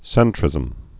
(sĕntrĭzəm)